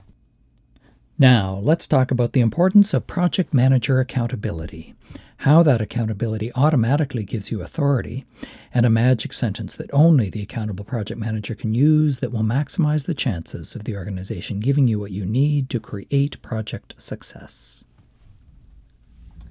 Here is a longer test, raw no processing.
Sounds like clicking within the microphone.